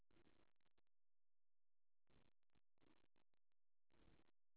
kyo 0141 (Monaural AU Sound Data)